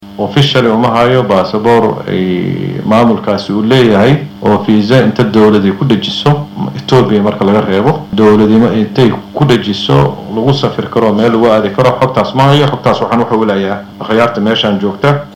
Wasiir Axmad Fiqi oo hortegay guddiga arrimaha dibadda ee baarlamaanka Soomaaliya, ayuu u sheegay, in Itoobiya ay u tagtay dalal badan oo saaxiib dhow la ah Soomaaliya, islamarkaasna ay kala hadashay in Soomaaliya aysan doonayn in ay kawada hadlaan khilaafka ka taagan heshiiska badda ee Somaliland ay la gashay.